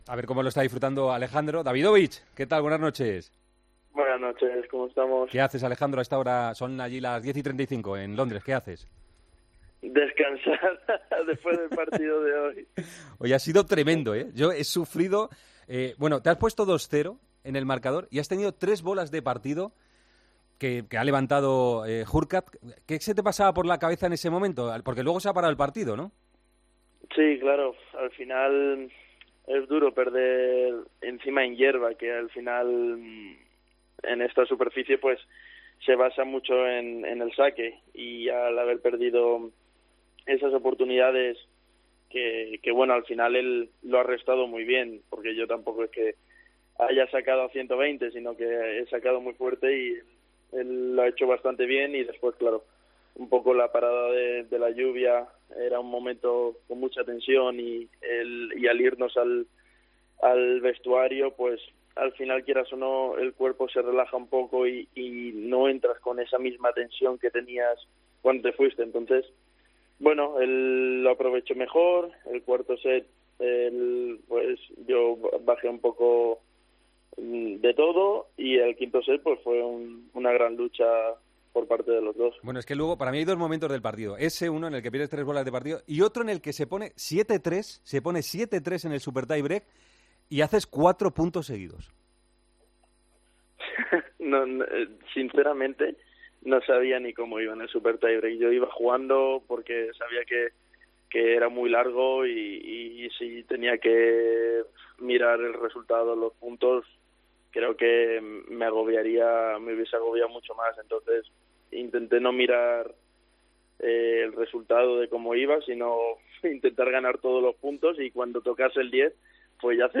ENTREVISTA A ALEJANDRO DAVIDOVICH, EN EL PARTIDAZO DE COPE